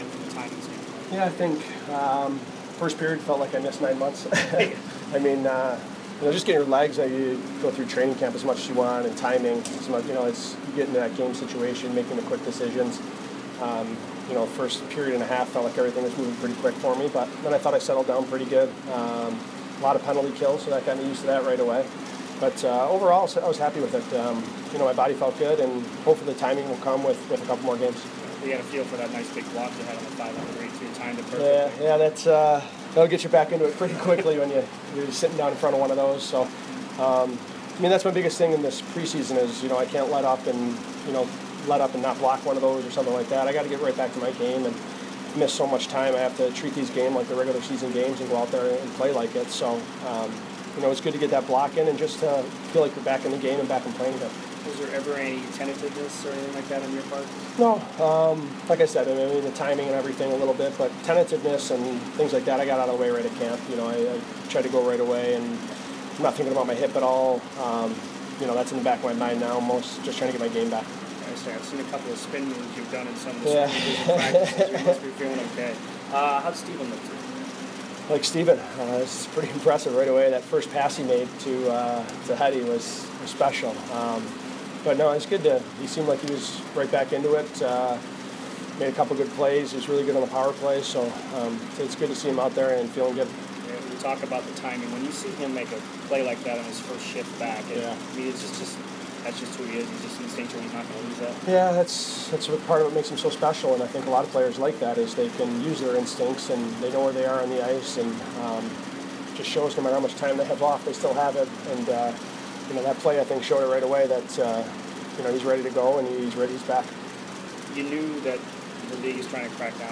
Ryan Callahan Post Game Interview 9/22
Callahan addresses the media after victory against Nashville.